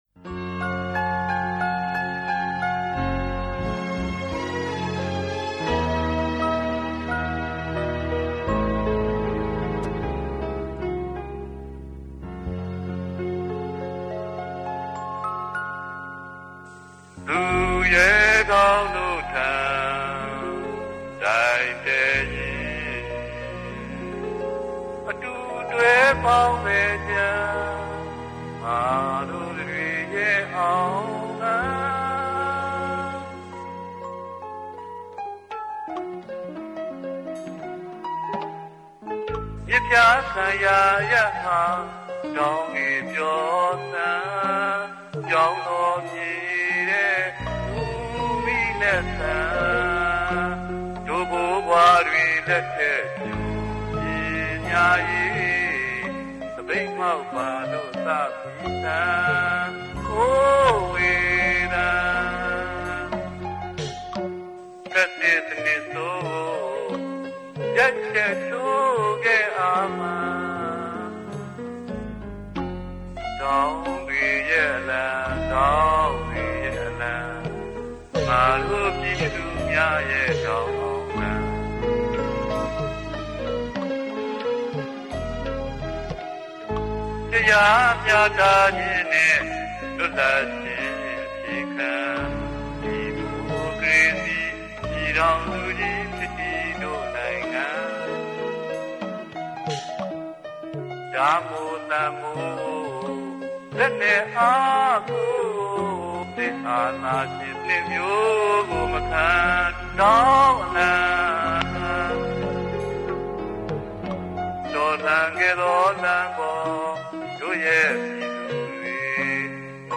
၁၉၈၈ ဒီမိုကရေစီအရေးတော်ပုံအမှတ်တရ ကိုမင်းကိုနိုင် ရေးသား သီဆိုတဲ့ ခွပ်ဒေါင်းအလံ သီချင်း ကို အခုနားဆင်ရမှာဖြစ်ပါတယ်။